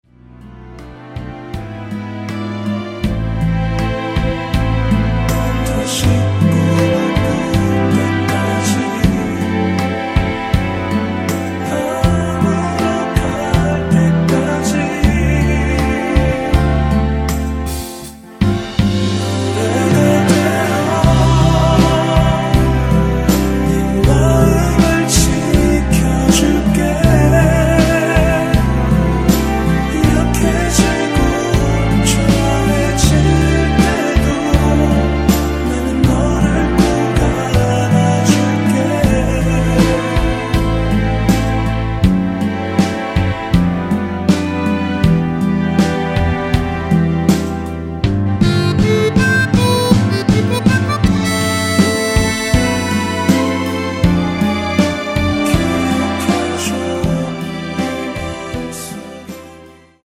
원키에서(-1)내린 코러스 포함된 MR 입니다.(미리듣기 확인)
앞부분30초, 뒷부분30초씩 편집해서 올려 드리고 있습니다.
중간에 음이 끈어지고 다시 나오는 이유는